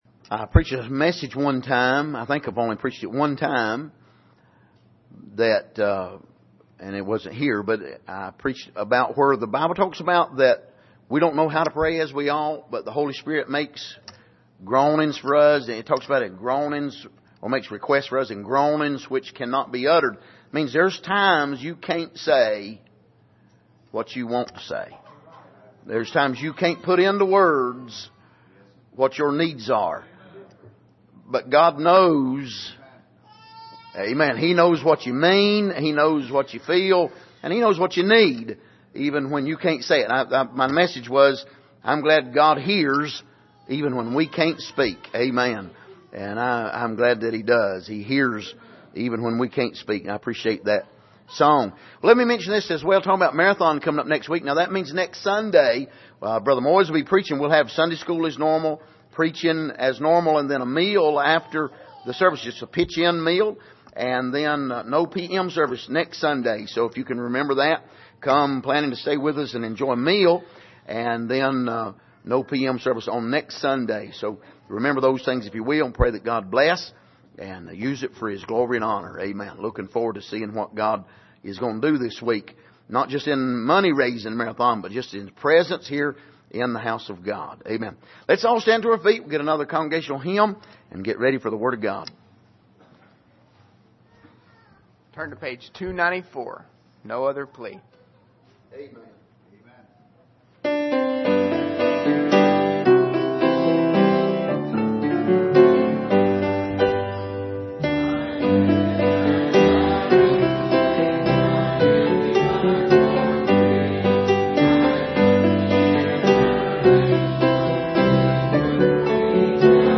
Passage: Revelation 6:9-11 Service: Sunday Morning